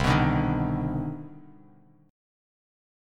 CmM9 Chord
Listen to CmM9 strummed